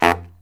LOHITSAX10-L.wav